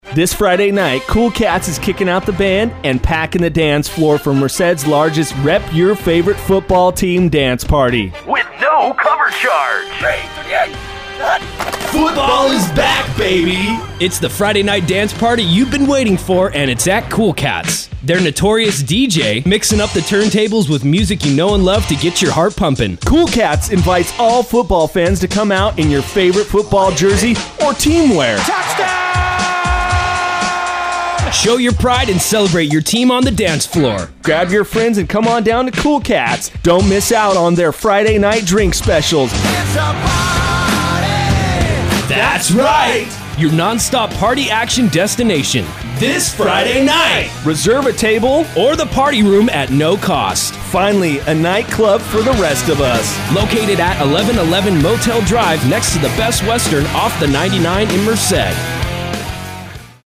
Check out our NEW radio spot on 92.5 The Bear!